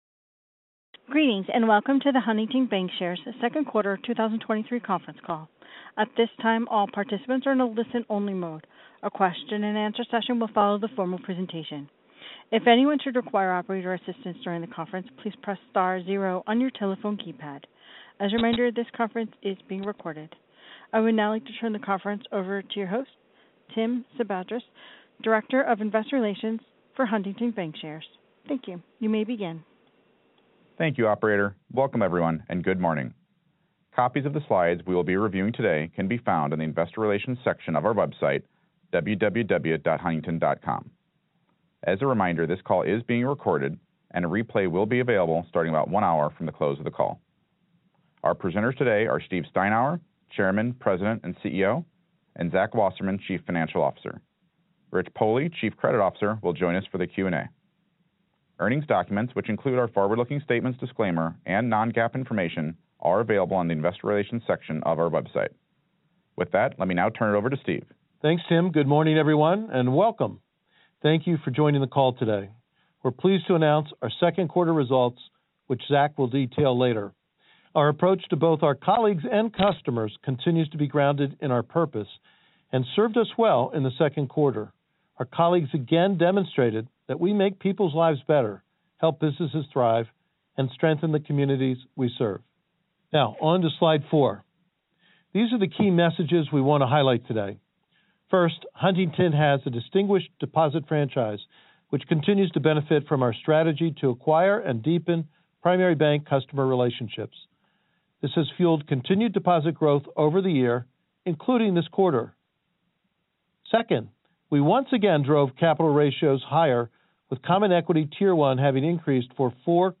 Huntington Bancshares 2023 Second Quarter Earnings Conference Call / Webcast